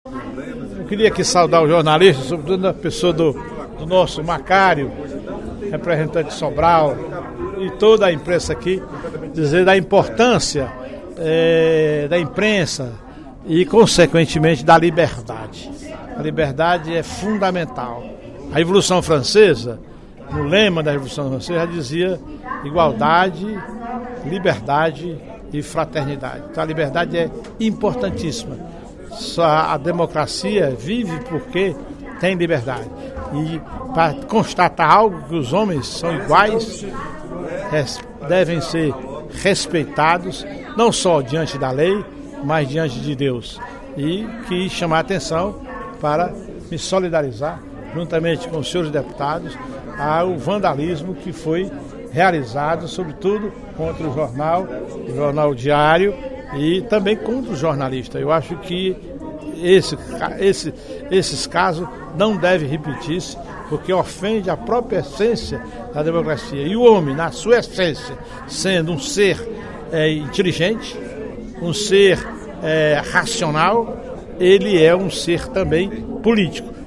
Na sessão plenária desta quarta-feira (30/05) da Assembleia Legislativa, o deputado Professor Teodoro (PSD) criticou os atos de vandalismo cometidos operários da construção civil.